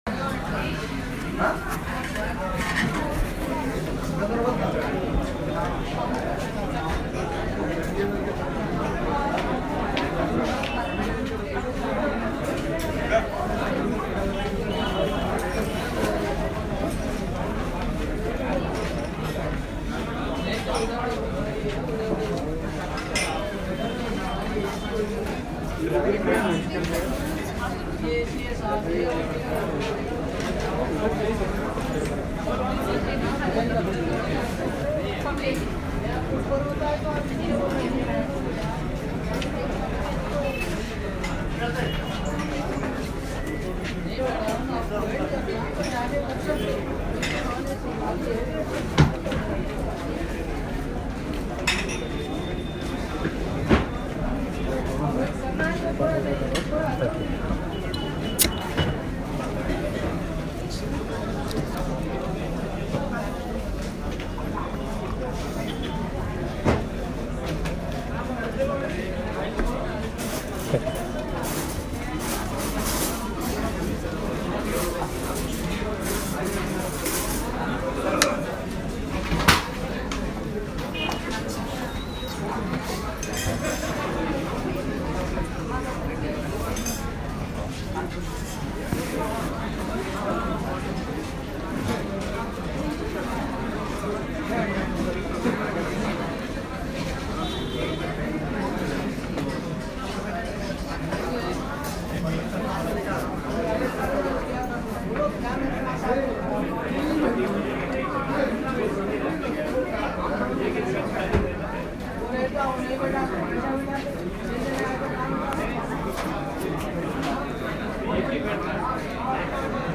A busy lunch service at the Hotel Shadab in Ghansi Bazar, Hyderabad.
This is a traditional biryani restaurant with open air seating near the street and aircon service upstairs. Even in the closed portion, the bustle of the city can be heard.